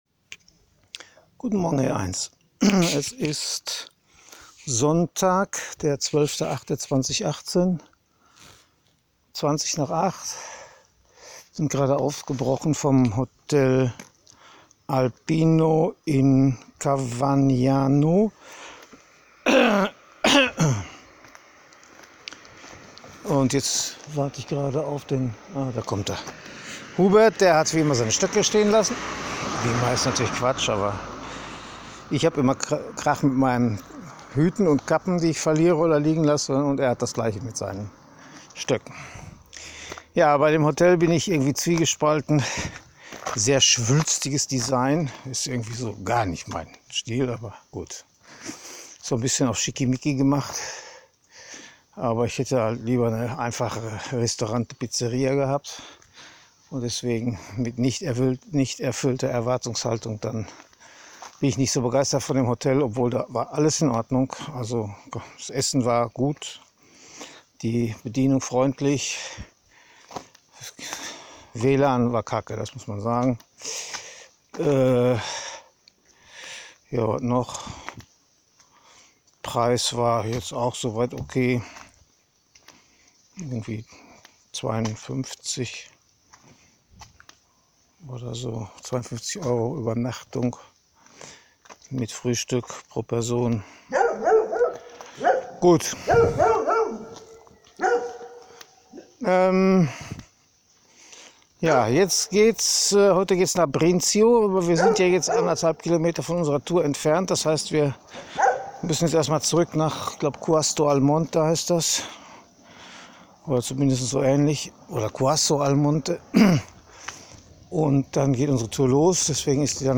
Audiotagebuch zum anhören